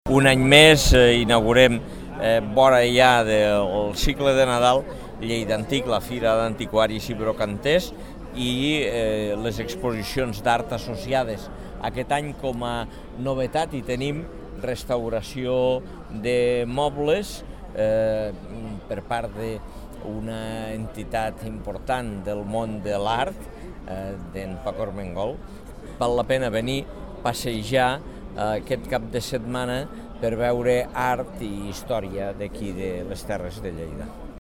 tall-de-veu-de-lalcalde-angel-ros-sobre-lleidantic-lleida-retro-i-la-trobada-del-disc-a-fira-de-lleida